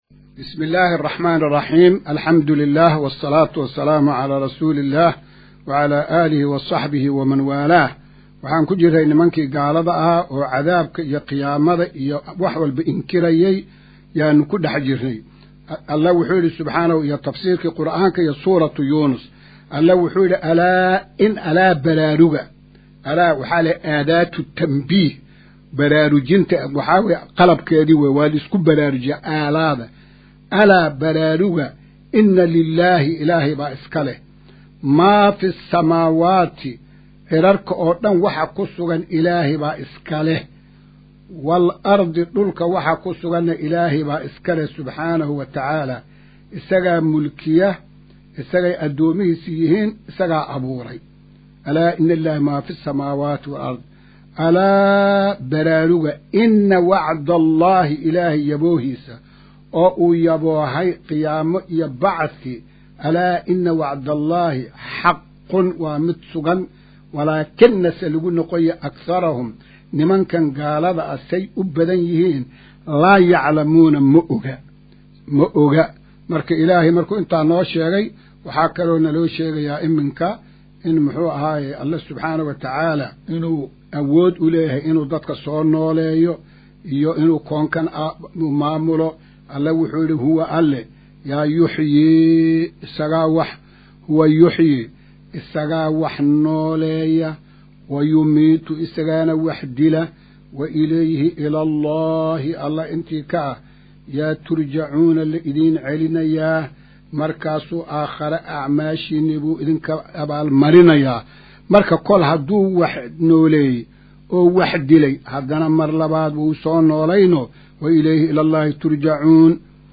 Casharka-107aad-ee-Tafsiirka.mp3